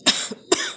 cough_3.wav